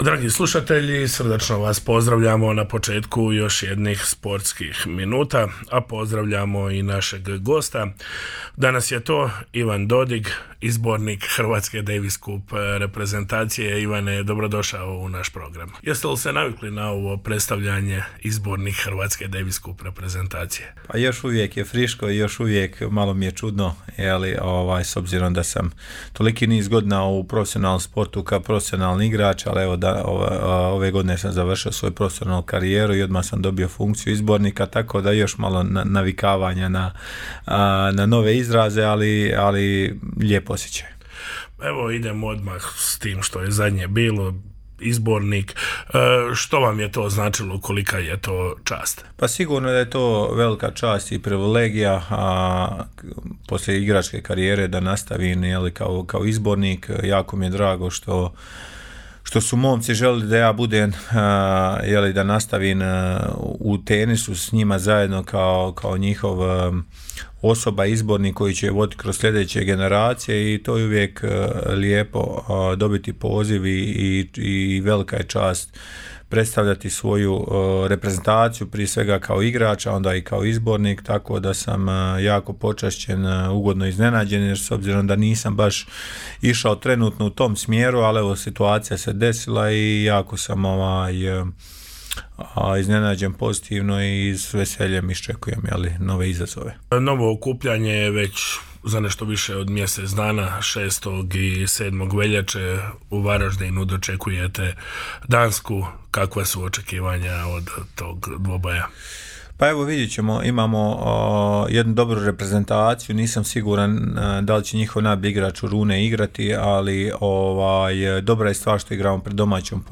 O tome je govorio u razgovoru za Sportske minute Radiopostaje Mir Međugorje, govorio je o trofejima, najljepšim i najtežim trenucima bogate karijere, treniranju mladih, ostvarenju svojih snova, počecima u Međugorju…